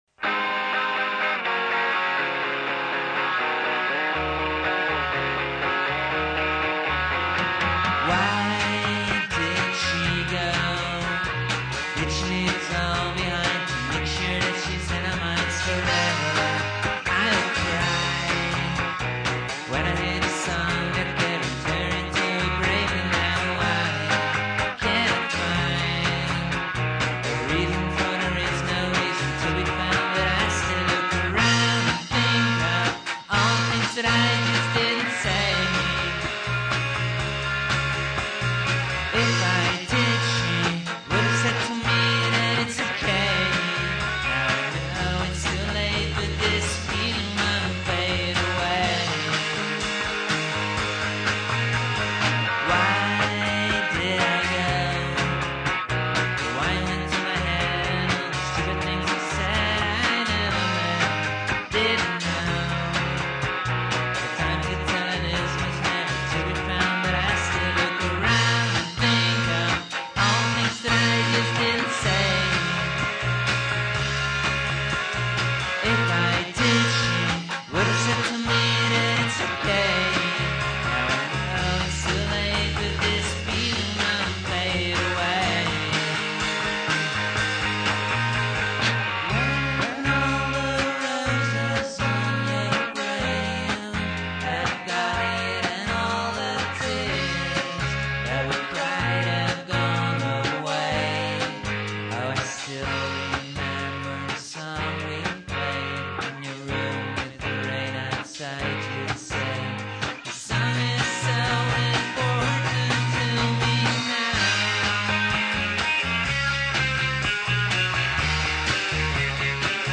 where: recorded at AMP (Amsterdam)
dreamy backing vocals